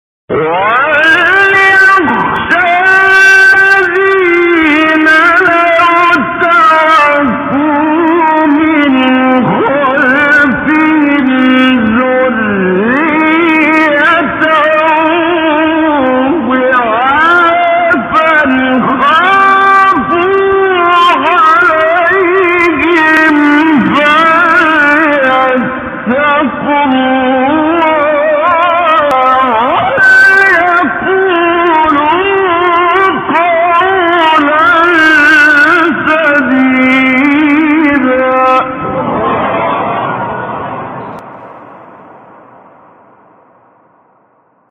تلاوت تقلیدی سوره نساء
مقام : رست